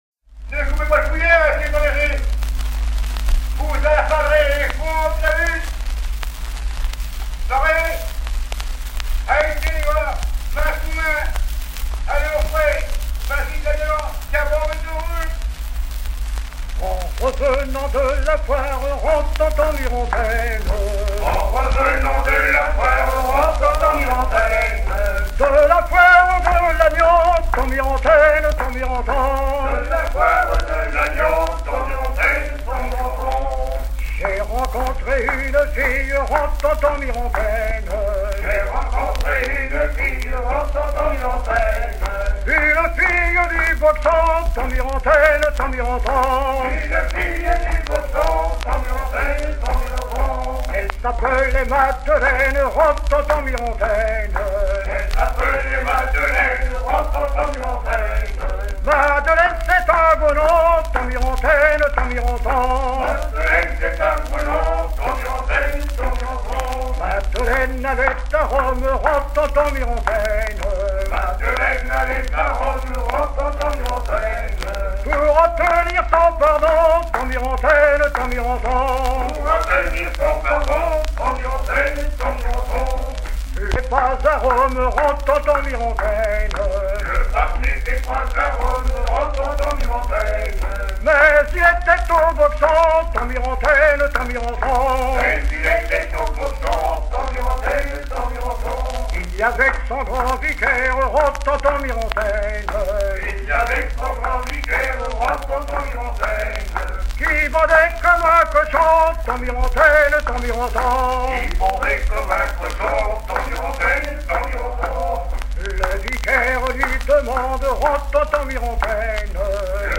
lors d'une mission du musée des Arts et Traditions populaires
gestuel : à virer au cabestan
Genre laisse